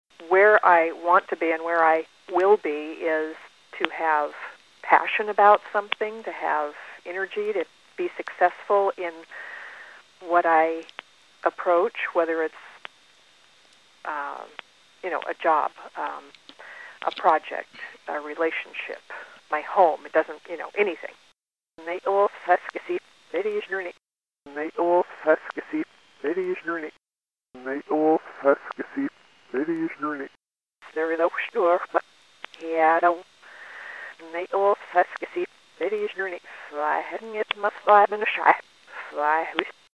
Tags: Reverse Speech Analysis Reverse Speech samples Reverse Speech clips Reverse Speech sounds Reverse Speech